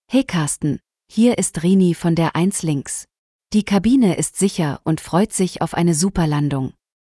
CallCabinSecureLanding.ogg